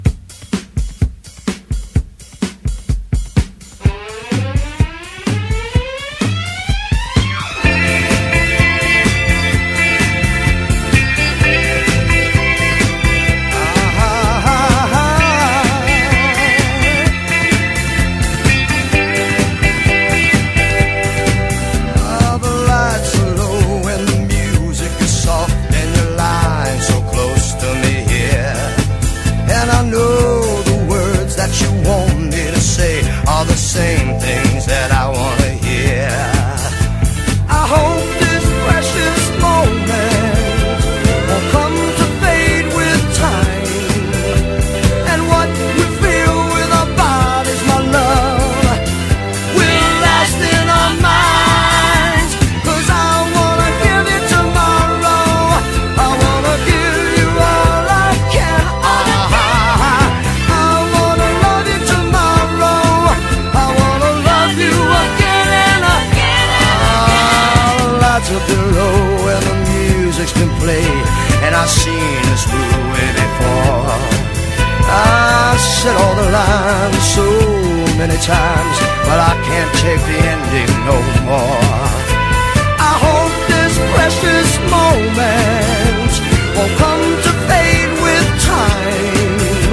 SOUL / SOUL / 60'S / NORTHERN SOUL / PHILADELPHIA SOUL